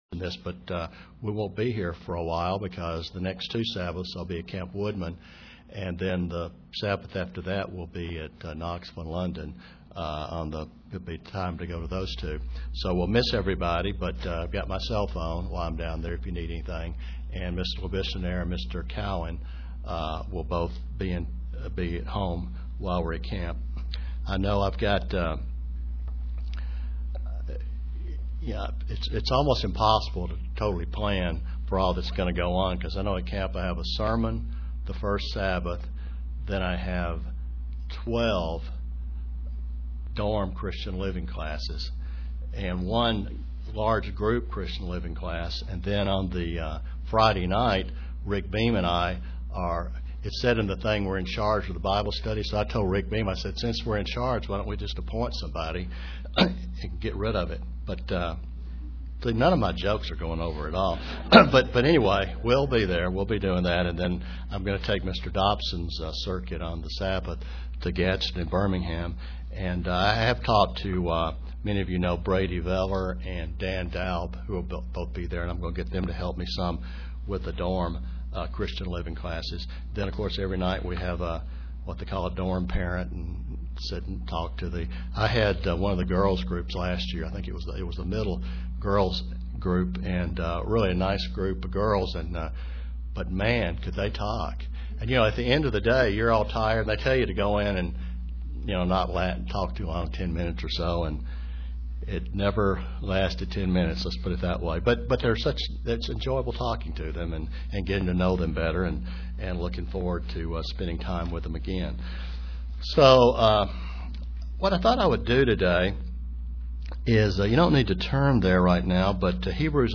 Given in Kingsport, TN
UCG Sermon Studying the bible?